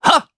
Zafir-Vox_Attack1_jp.wav